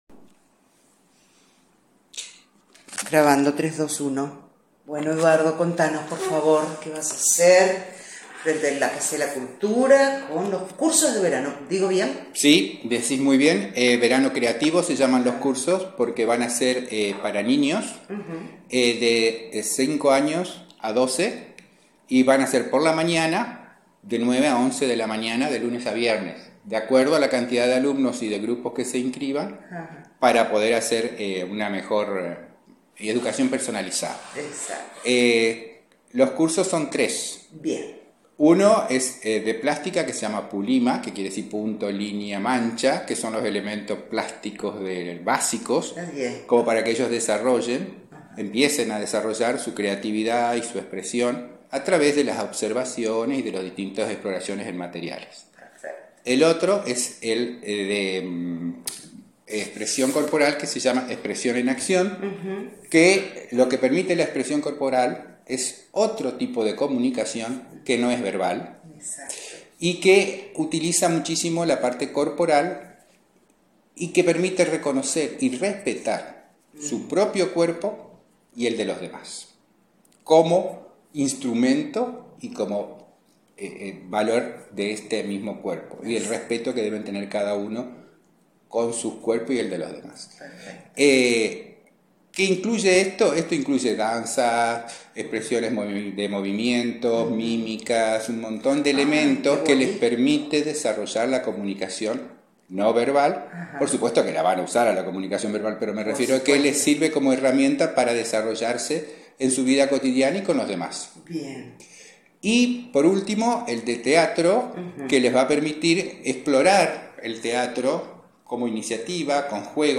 Escuchá la nota completa que ilustra el Director de Cultura y Educación de la municipalidad de Vera, el profesor Eduardo Perot brindando todos los detalles.